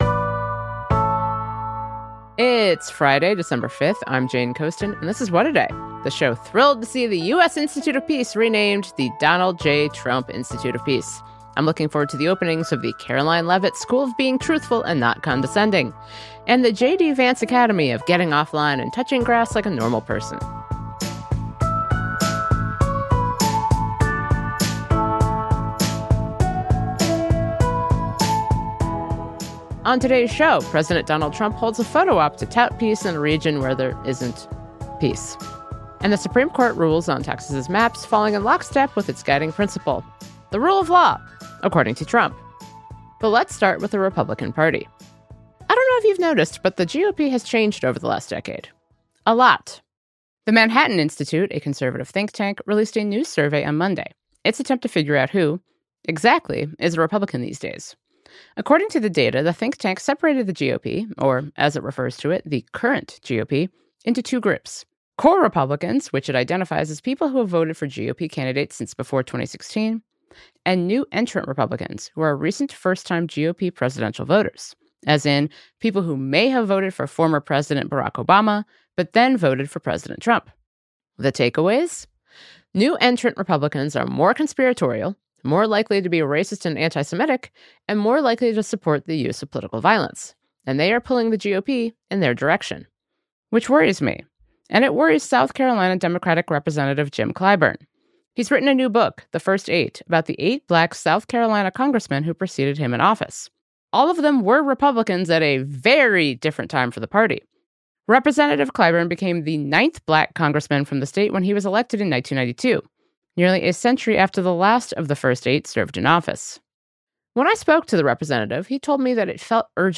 We spoke with Representative Clyburn about why it felt so urgent to write this book now.